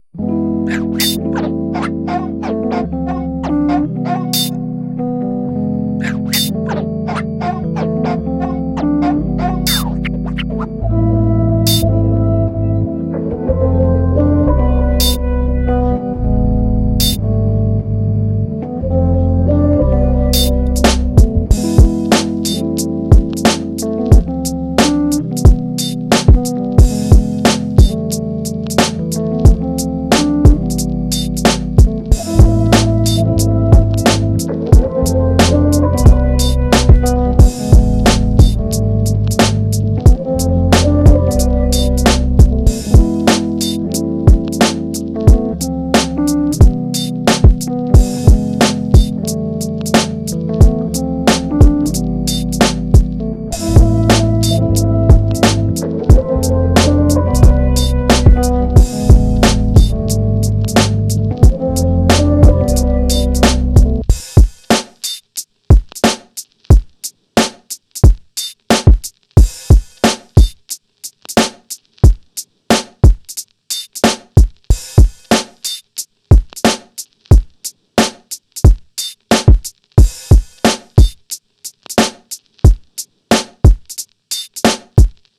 VERY old beat I found on my laptop
off beat and painful to listen